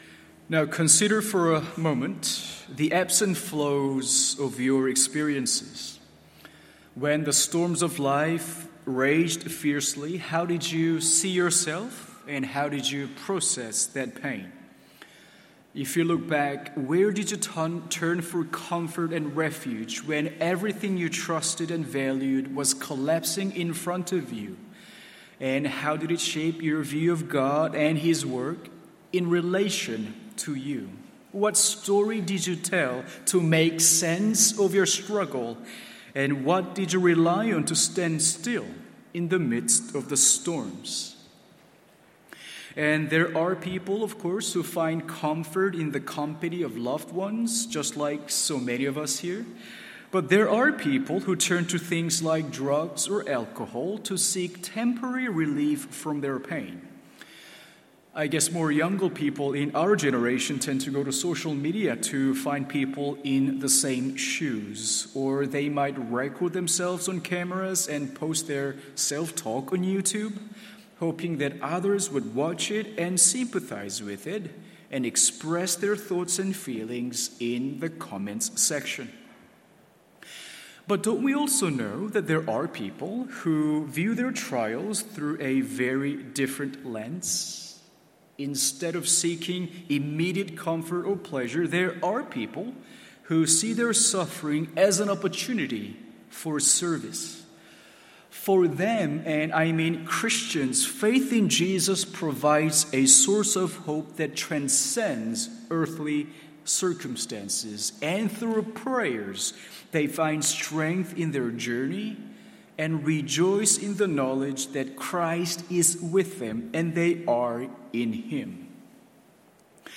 MORNING SERVICE Philippians 1:12-18…